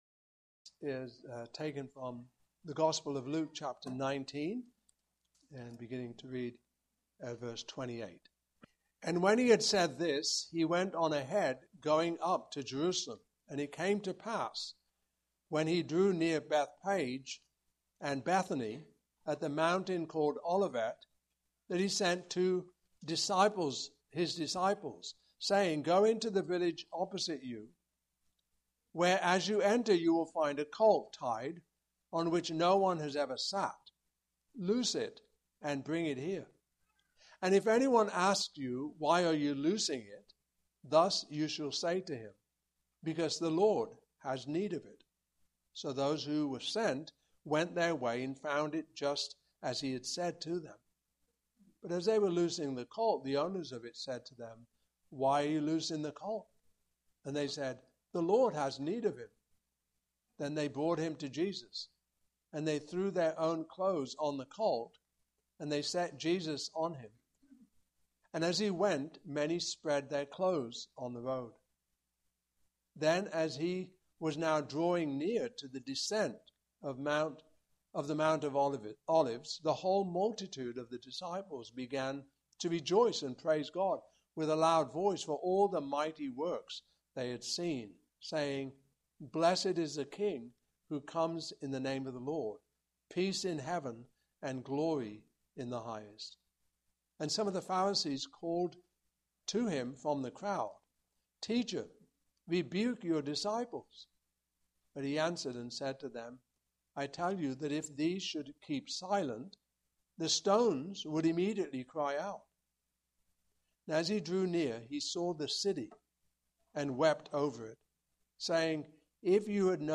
Passage: Luke 19:28-48 Service Type: Morning Service Topics